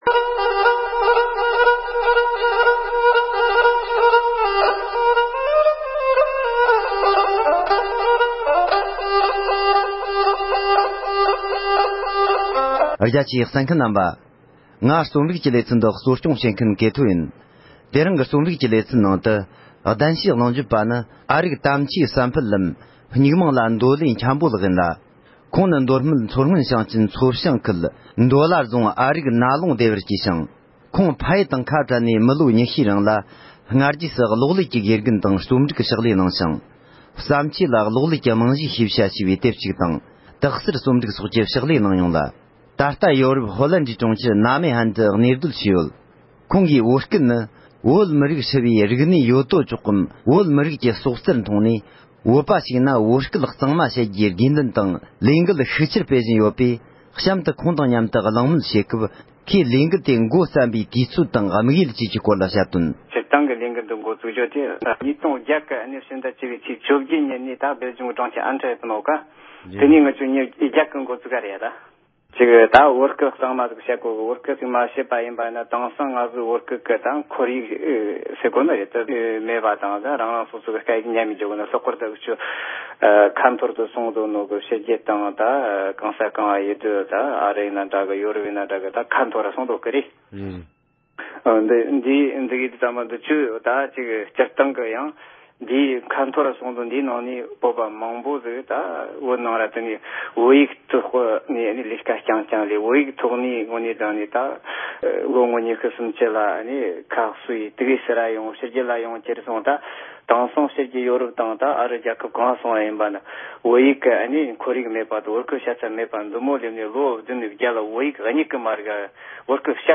བོད་སྐད་གཙང་མ་བཤད་དགོས་པའི་སྐོར་གླེང་མོལ།